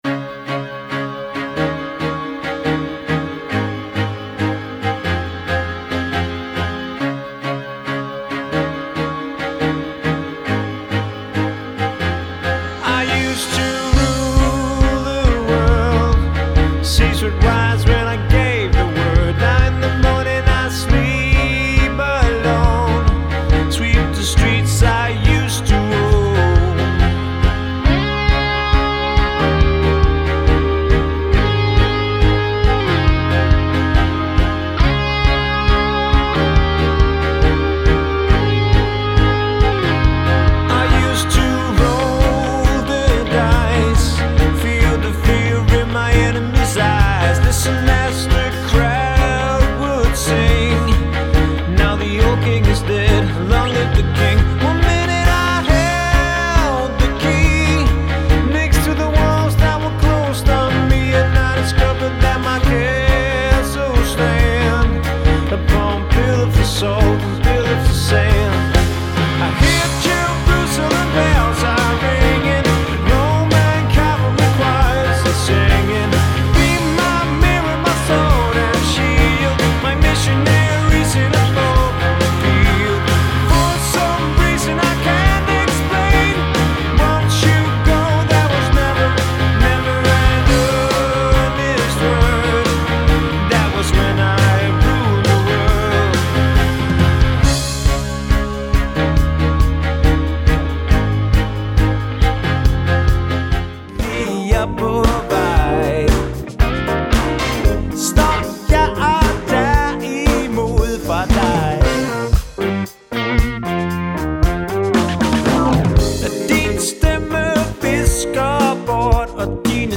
• Allround Partyband
• Coverband
Medley